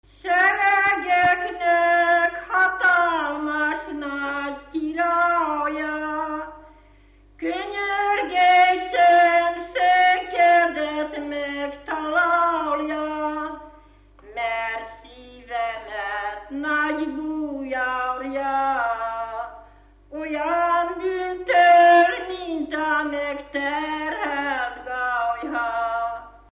Dunántúl - Verőce vm. - Haraszti
Stílus: 5. Rákóczi dallamkör és fríg környezete
Kadencia: 4 (4) b3 1